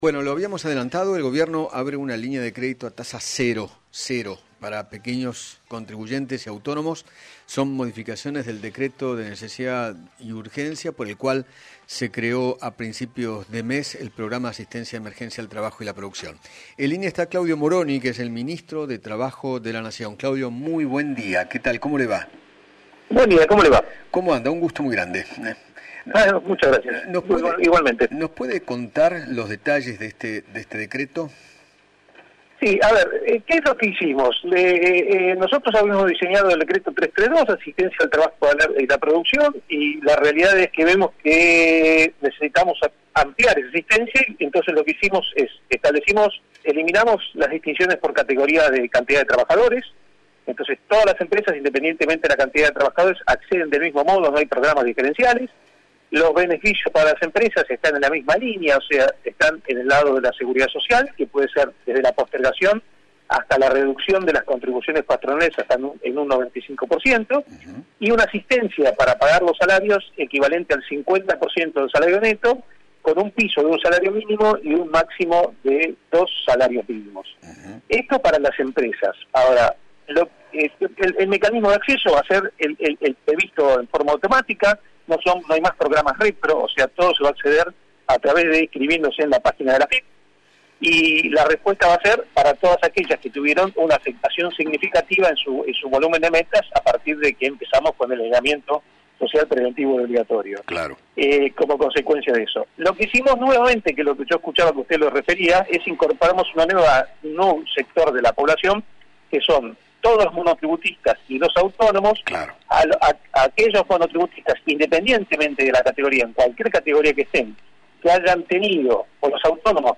Claudio Moroni, Ministro de Trabajo de la Nación, dialogó con Eduardo Feinmann sobre la nueva medida de crédito a tasa 0 que anunció el Gobierno dentro del Programa de Asistencia de Emergencia al Trabajo y la Producción.